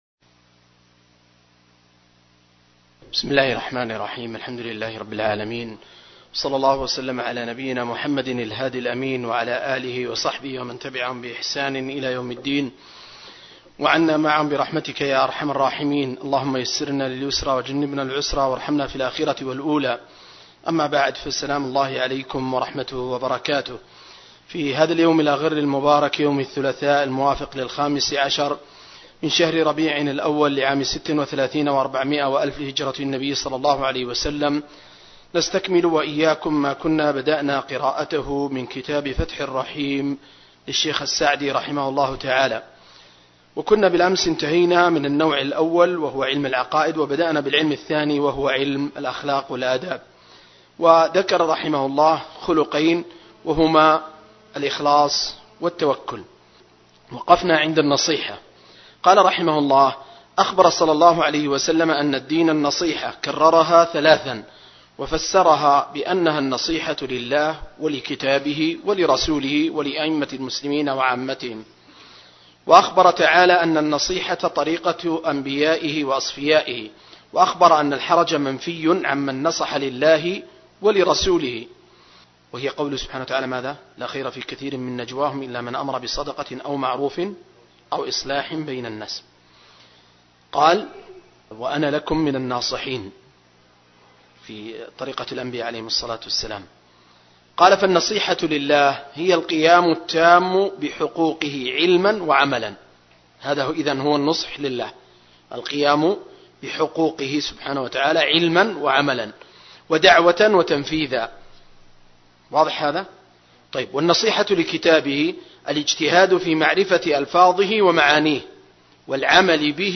دورة علمية في قاعة الدروس والمحاضرات